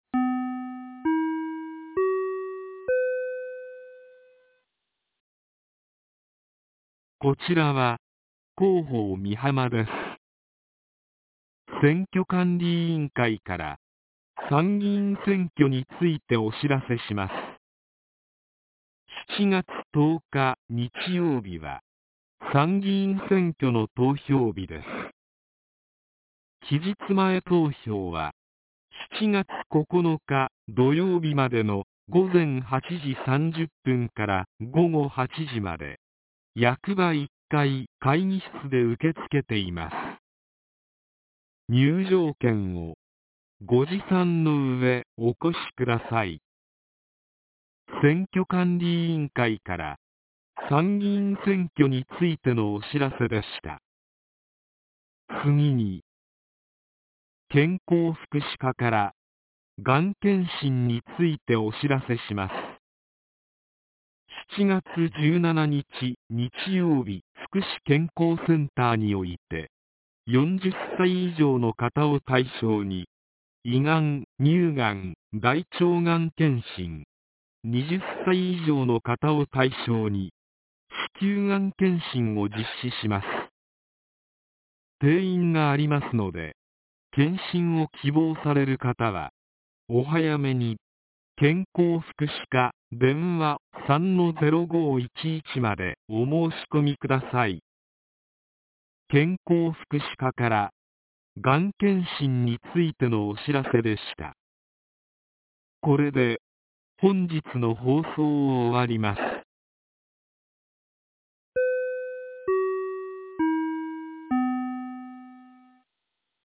■防災行政無線情報■
放送音声